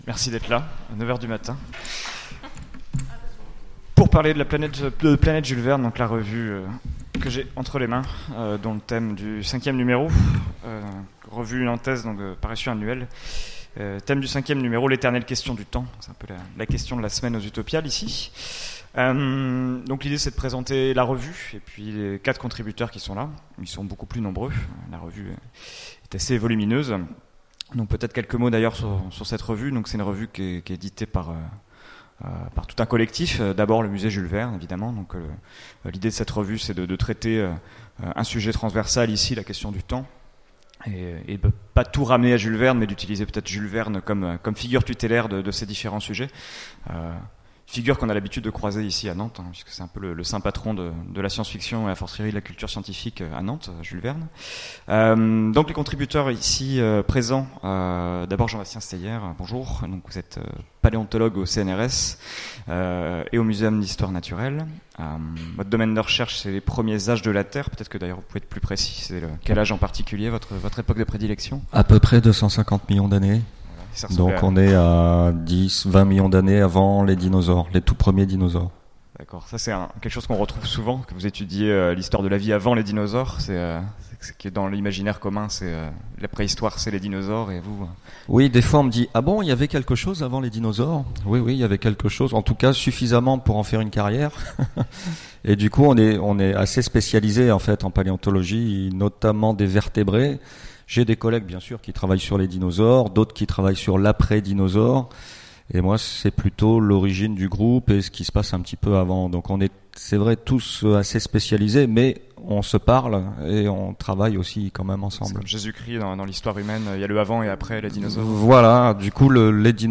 Utopiales 2017 : Conférence Planète Jules Verne : le temps et l’éternité